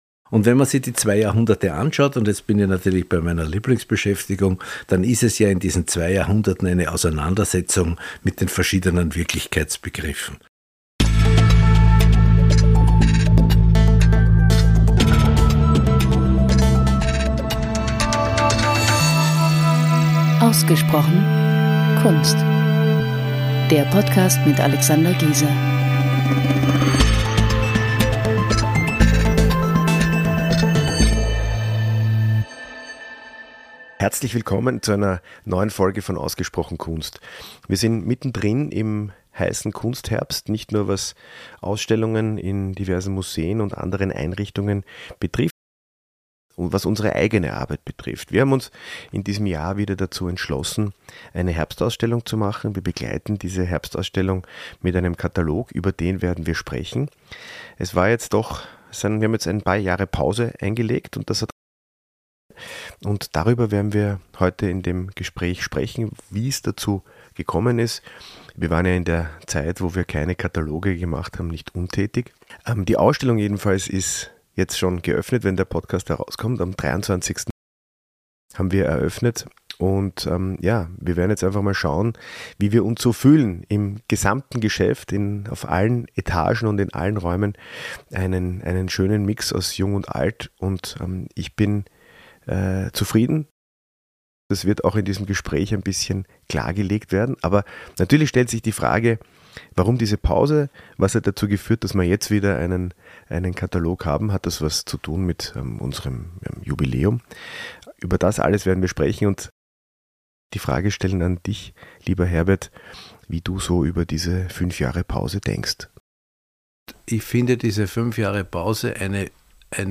Wenn wir eine Ausstellungsbesprechung machen, dann setzen wir uns vor die Mikros und legen los.